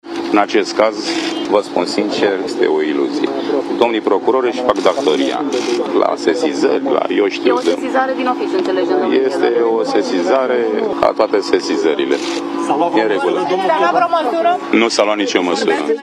Piedone a dat primele declarații după audierile la sediul DNA.
„Acest caz este o iluzie….” a declarat Cristian Popescu Piedone după audierile la sediul DNA.